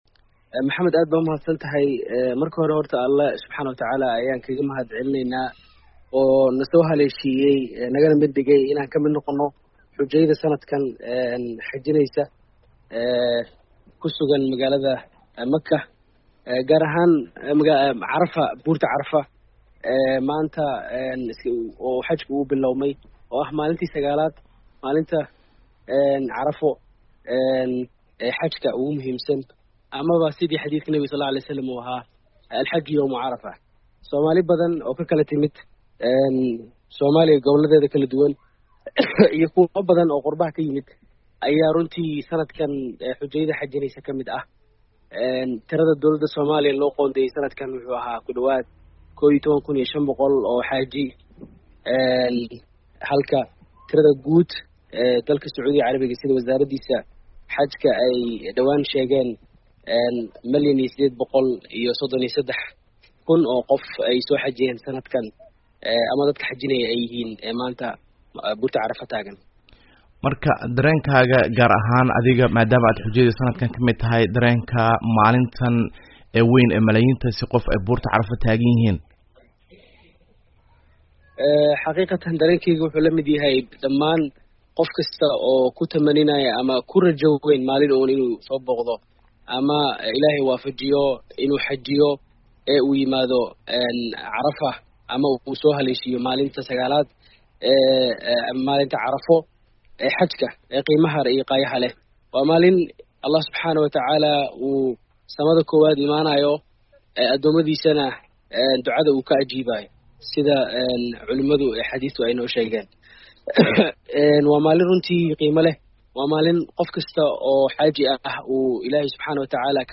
Wareysi ku saabsan Xujeyda maanta taagnaa Banka Carafo